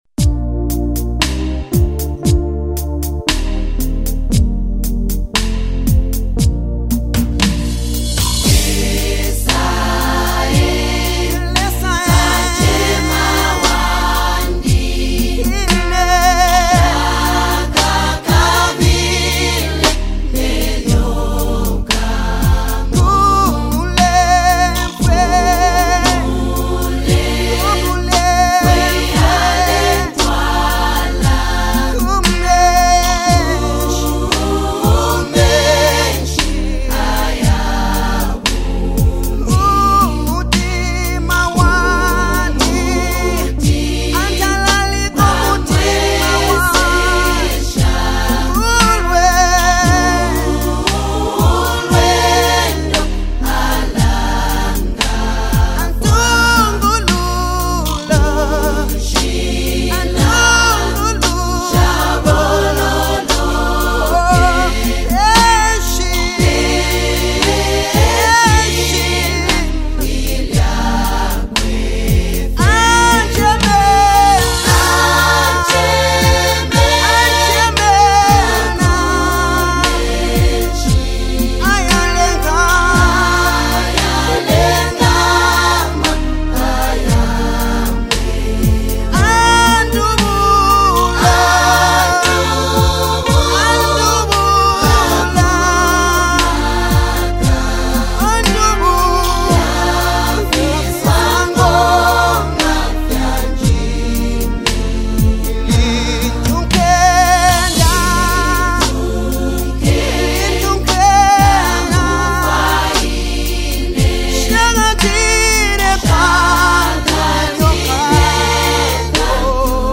Zambia’s award-winning gospel minister
heartfelt delivery and soulful melodies